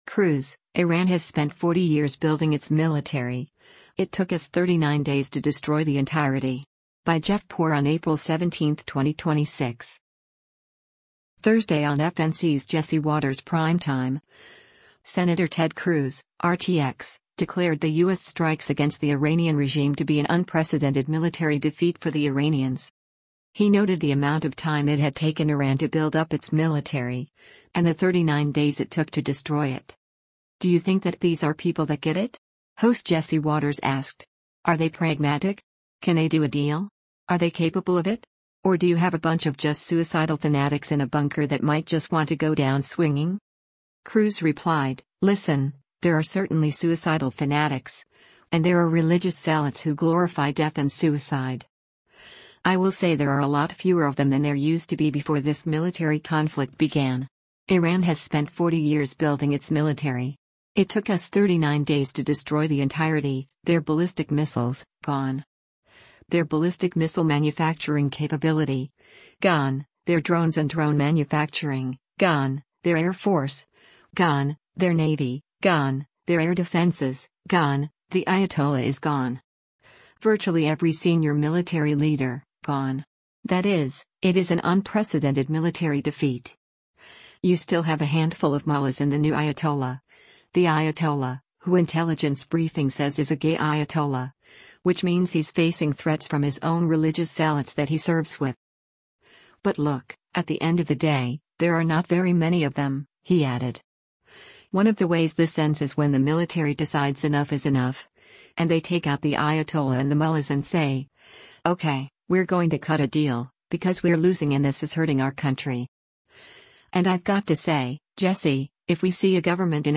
Thursday on FNC’s “Jesse Watters Primetime,” Sen. Ted Cruz (R-TX) declared the U.S. strikes against the Iranian regime to be an “unprecedented military defeat” for the Iranians.